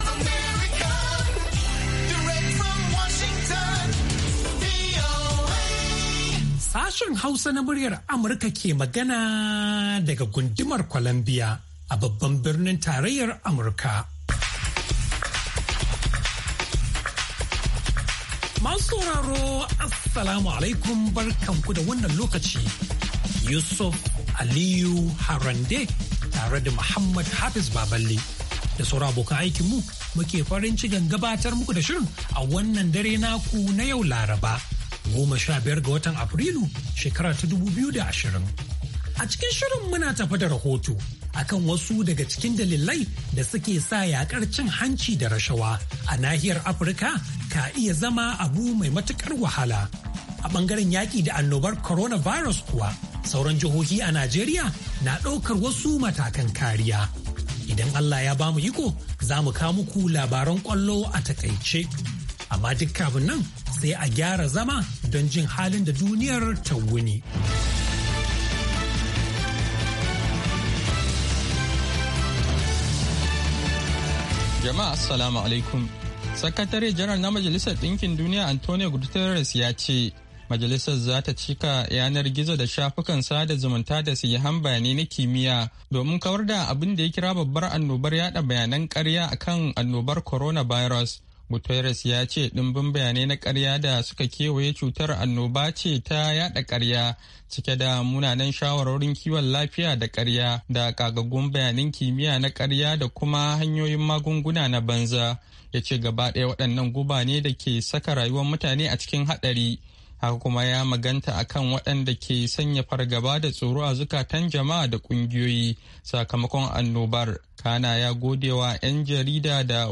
A shirin namu na karfe 9 da rabi agogon Najeriya da Nijar, zaku ji labarai na yadda duniyar ta yini da rahotanni da dumi-duminsu, sannan mu kan bude muku layuka domin ku bugo ku bayyana mana ra’ayoyinku kan batutuwan da suka fi muhimmanci a wannan rana, ko kuma wadanda ke ci muku tuwo a kwarya.